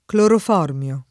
[ klorof 0 rm L o ]